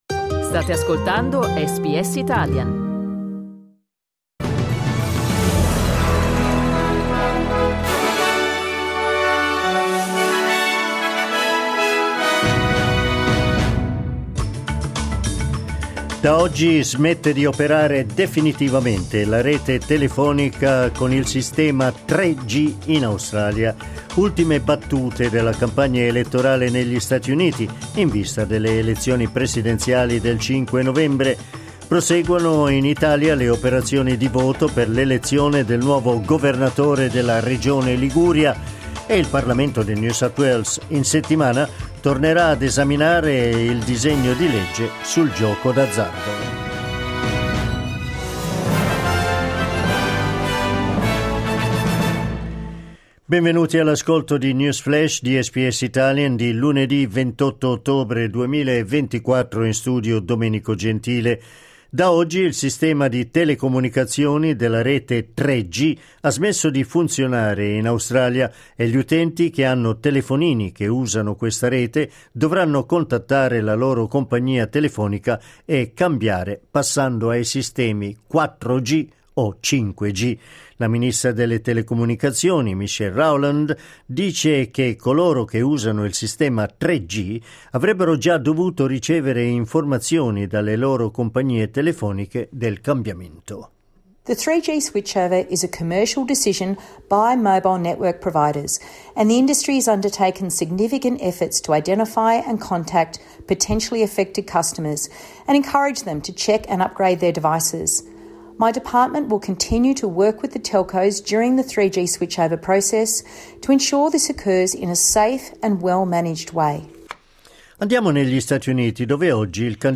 News flash lunedì 28 ottobre 2024
L’aggiornamento delle notizie di SBS Italian.